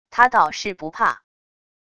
他到是不怕wav音频生成系统WAV Audio Player